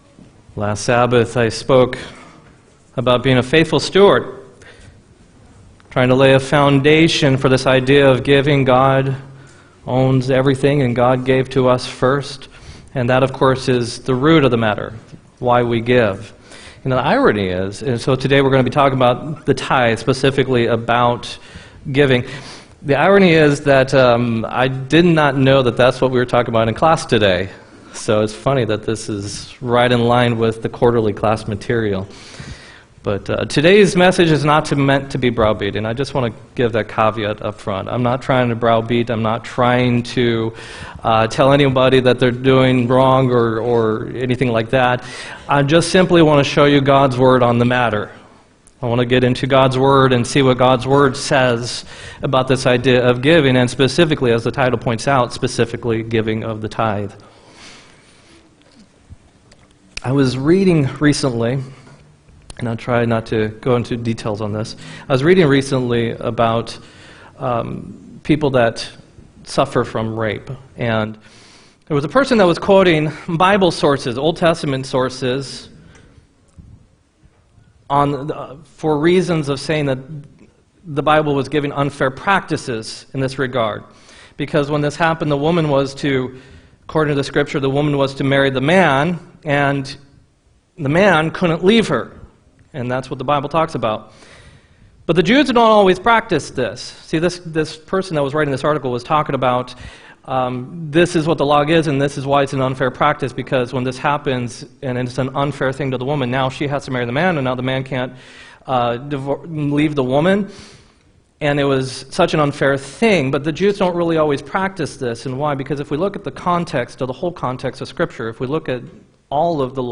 11-17-18 sermon